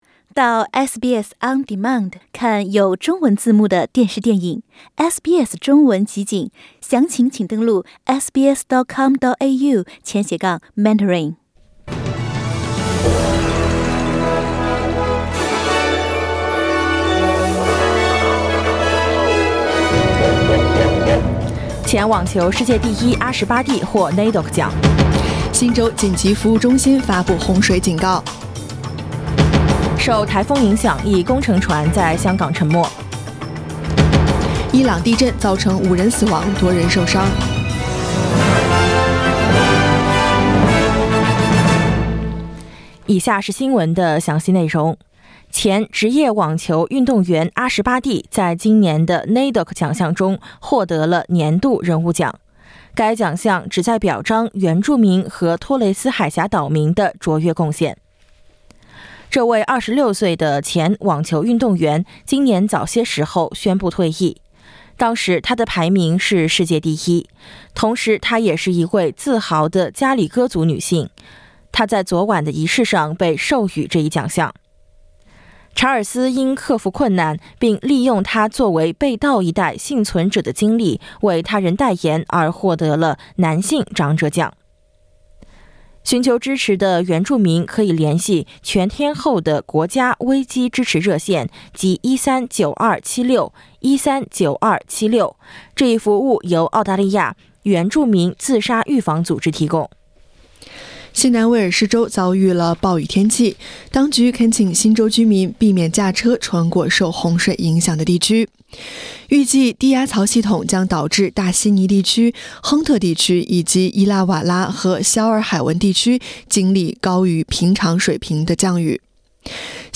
SBS早新聞（2022年7月3日）